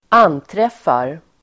Ladda ner uttalet
Uttal: [²'an:tref:ar]